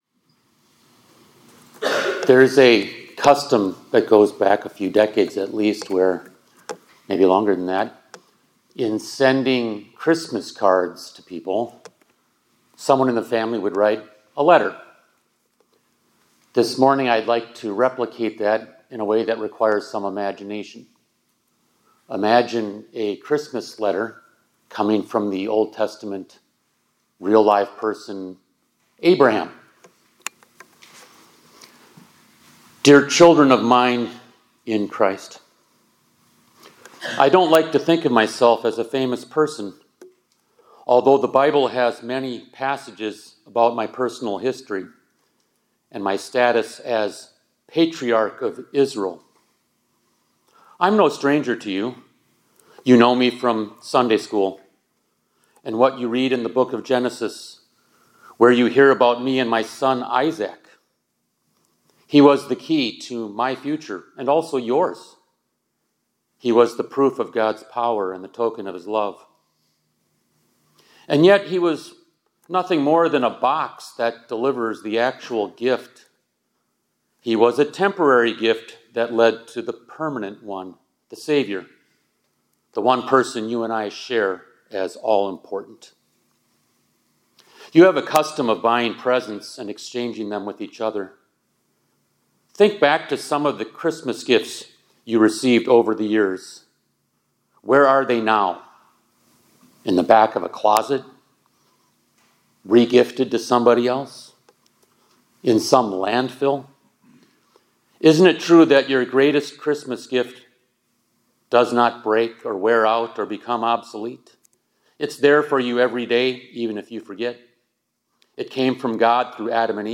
2024-12-03 ILC Chapel — A Christmas Letter From Abraham